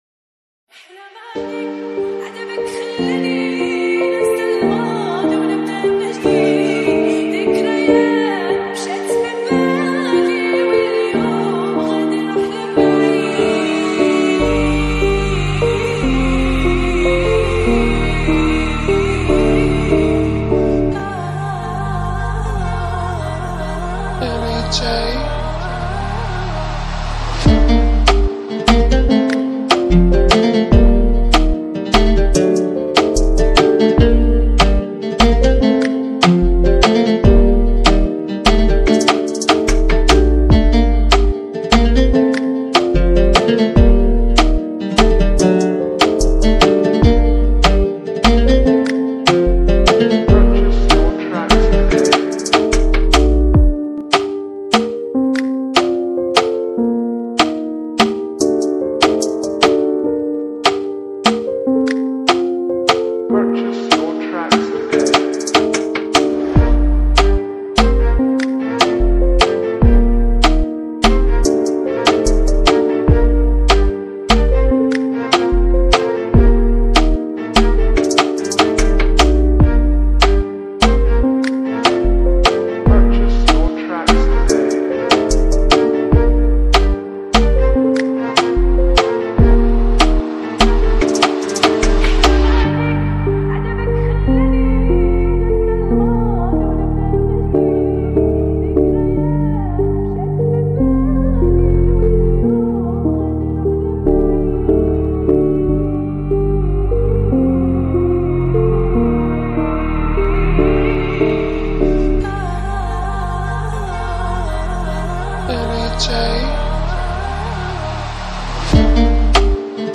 Arabic Remix Song